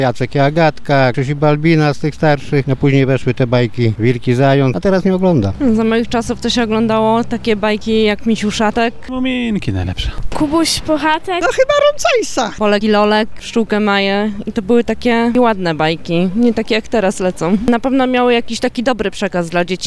sonda- postacie z bajek.mp3